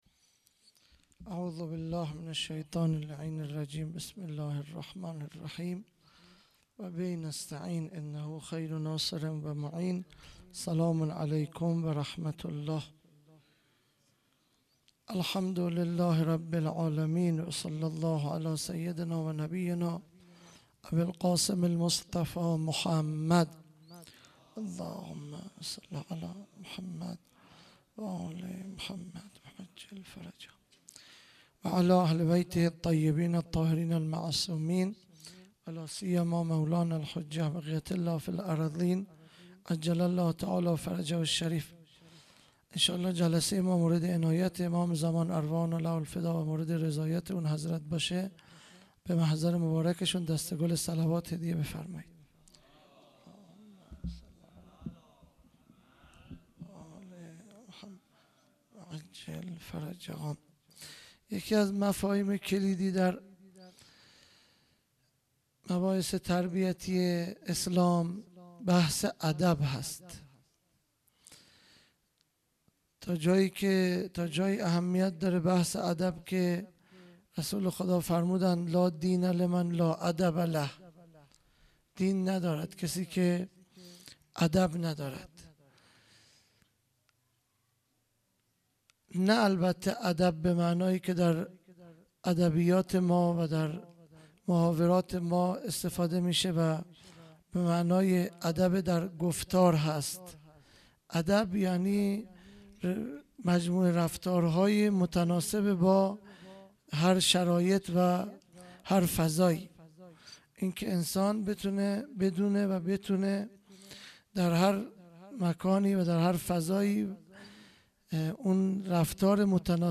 خیمه گاه - هیئت بچه های فاطمه (س) - سخنرانی